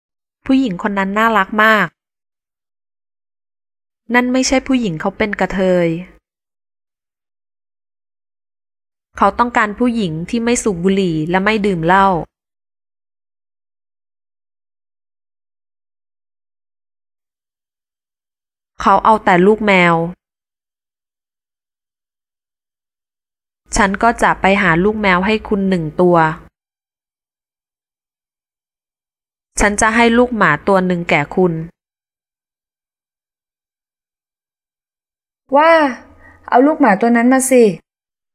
Thai Language Course is a 300 page book with 2 CDs. Speakers come from Bangkok, Pattaya, and Chiang mai.